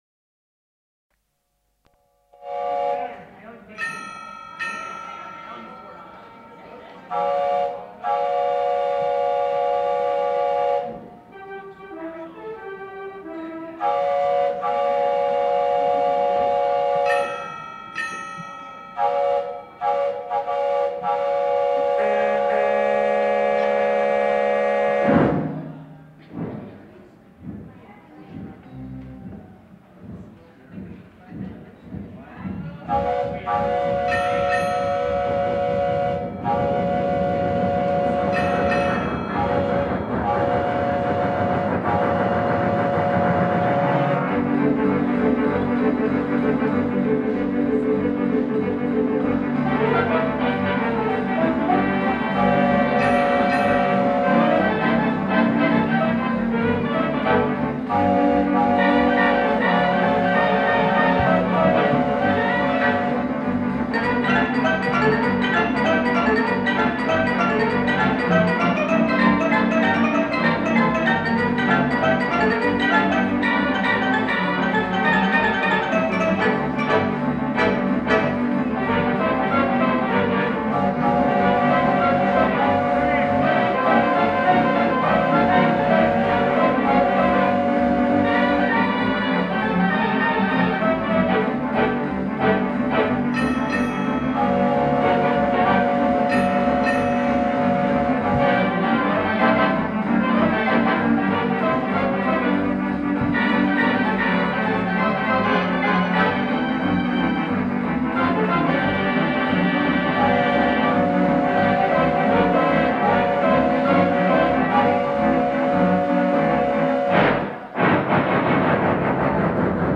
In the archives we find an original tape recorded circa 1970 in California on a UHER portable machine. (4000 report-L, 7.5 “/sec, mono, half track) – Pizza & Pipes at the “Cap’ns Galley”.
web pics of the Uher recorder used in the late 1960’s for recording the Cap’ns Galley organ.